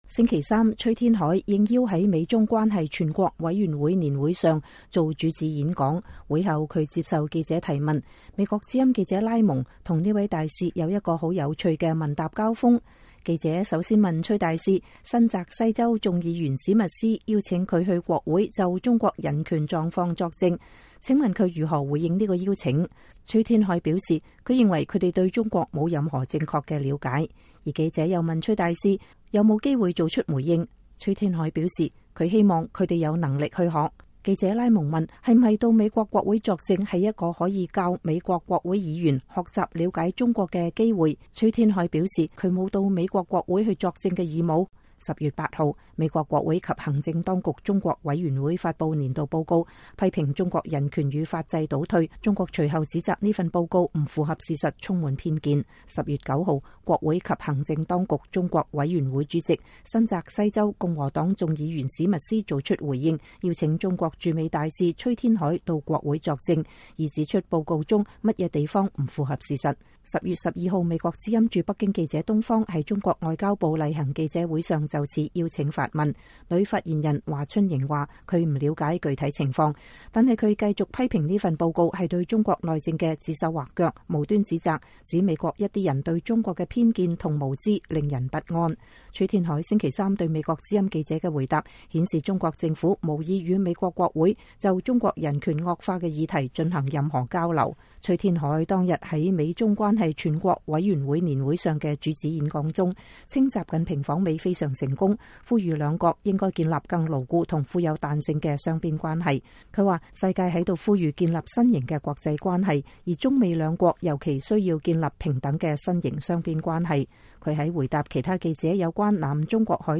會後他接受記者提問